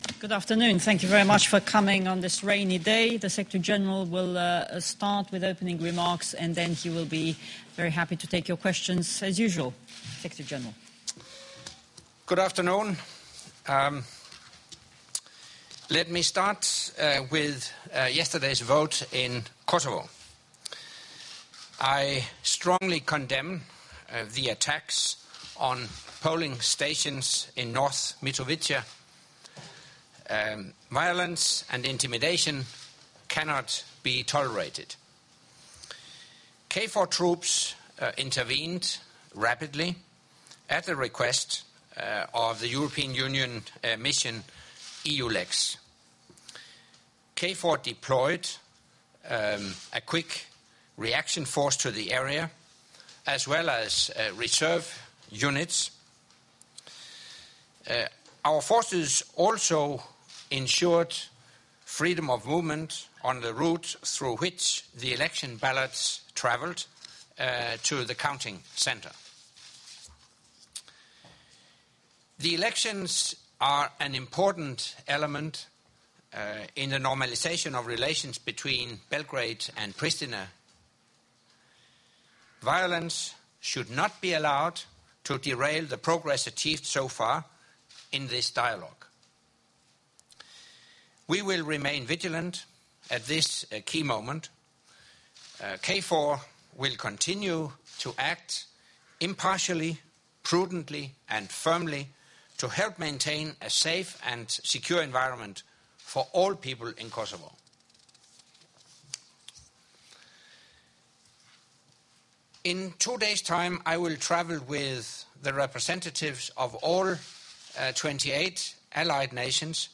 Monthly press conference by NATO Secretary General Anders Fogh Rasmussen held at the Résidence Palace, Brussels